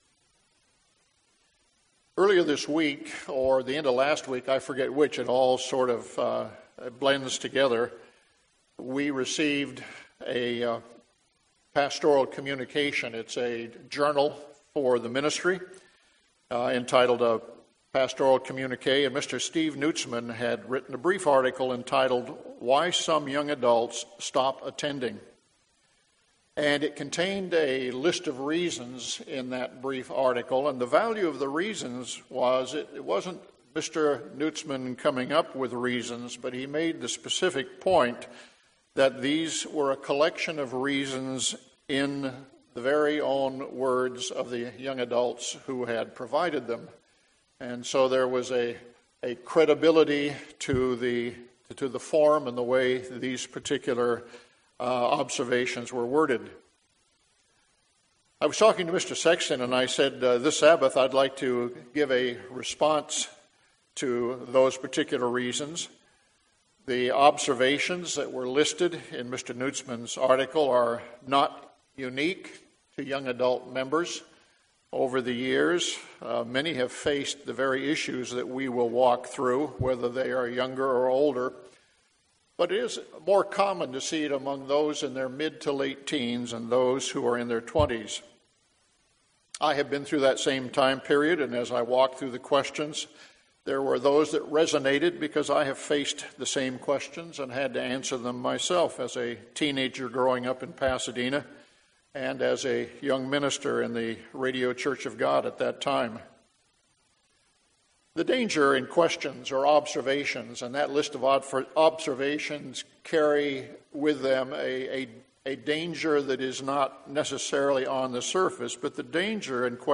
The sermon will explore reasons given by young adults and provide a scriptural response to their observations.